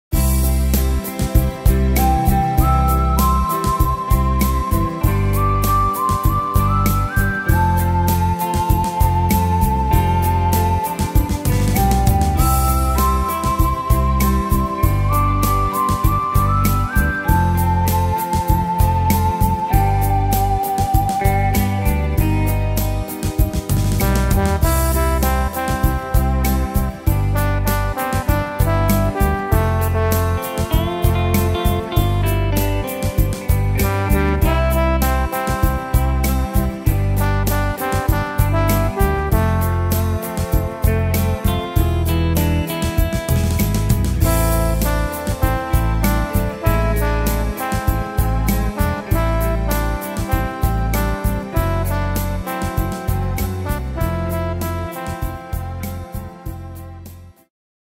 Tempo: 98 / Tonart: C-Dur